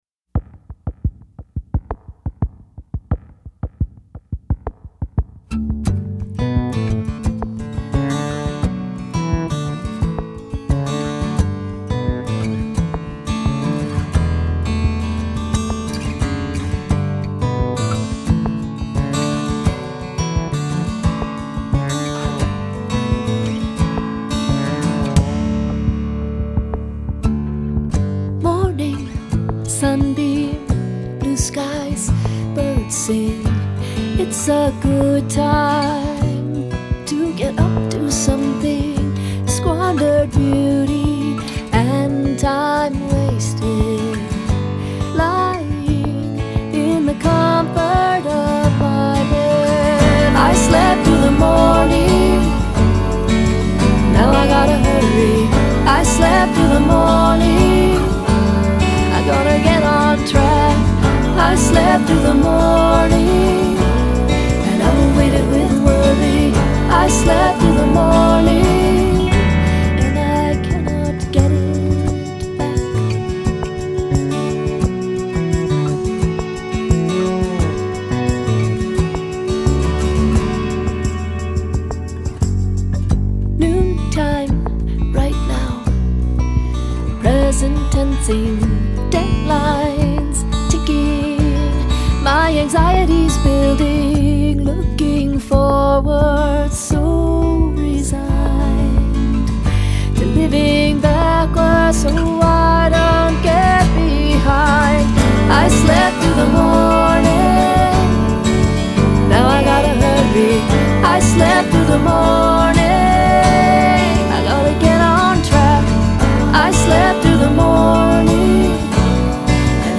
Midtempo Fm vx, Acoustic Band